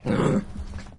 Doorbell Ringing With Three Dogs Barking Poodle, Chihuahua, Chinese Crested Powderpuff
标签： barking chihuahua chinese crested dogs doorbell poodle powderpuff ringing three
声道立体声